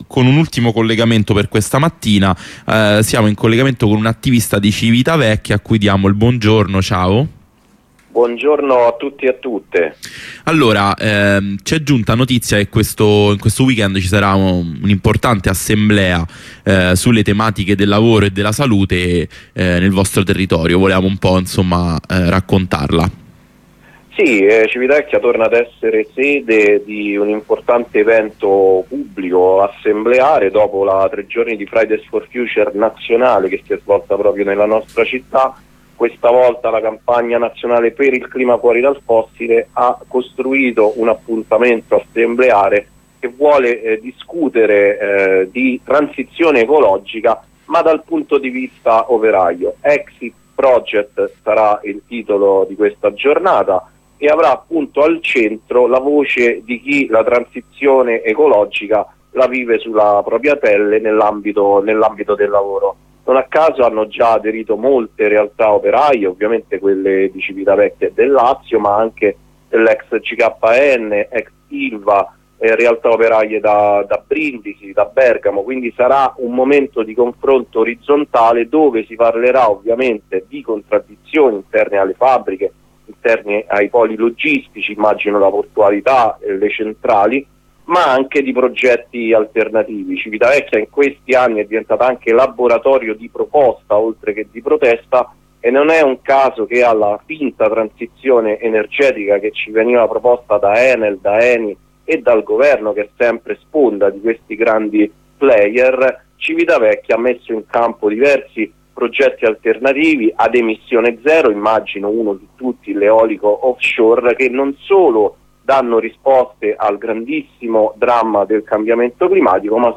Attivista di Civitavecchia